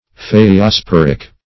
-- Ph[ae]`o*spor"ic , a. [1913 Webster]